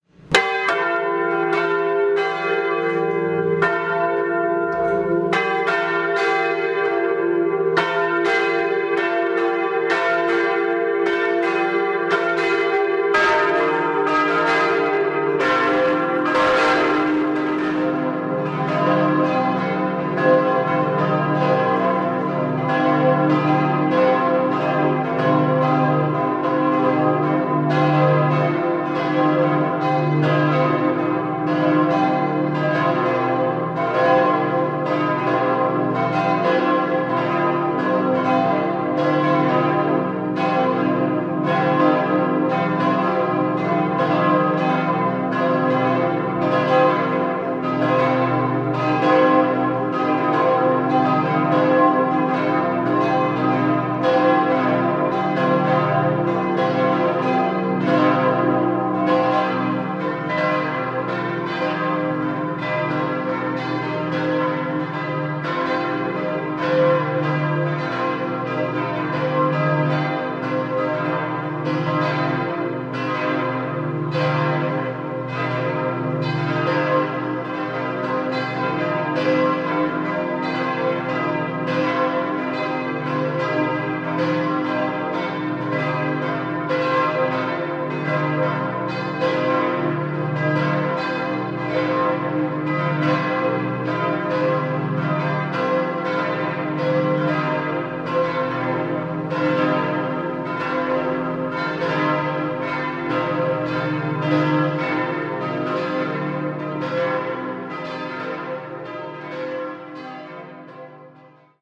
Bestellt wurde bei der Hemelinger Firma Otto ein Geläut aus vier Bronzeglocken:
>> eine große C-Glocke (2800 kg) „Salvator“, gewidmet dem Andenken der im Ersten Weltkrieg gefallenen Soldaten
>> eine Es-Glocke (1650 kg) „St. Marien“ mit der Inschrift „Gegrüßet seist du Königin, der Menschen Trost und Helferin, Ave Maria“
>> eine F-Glocke (1150 kg) „Caritas“, dem Andenken der Frau des Stifters gewidmet
>> eine G-Glocke (800 kg) „St. Joseph“ mit der Inschrift „St. Joseph, Schirmherr der Kirche, schütze uns und unsere Familien“.
Glocken-St.-Marien-Dom-Hamburg.mp3